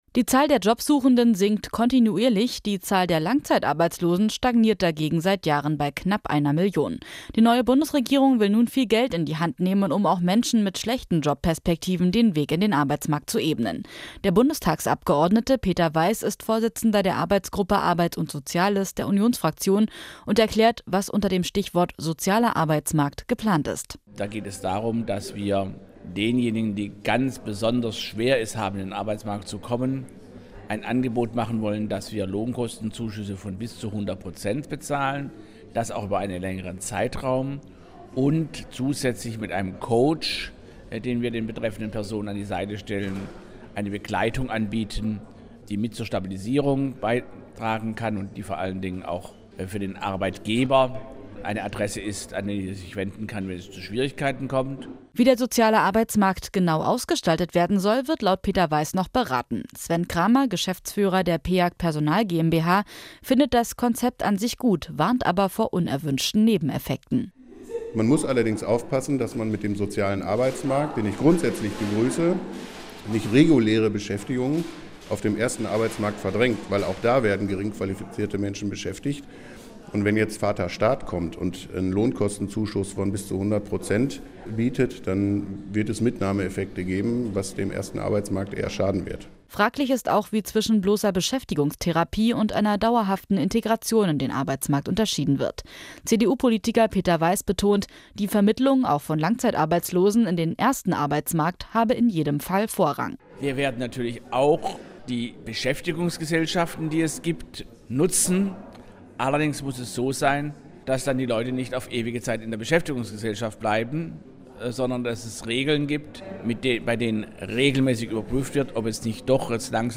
Allgemein, O-Töne / Radiobeiträge, Politik, , , , ,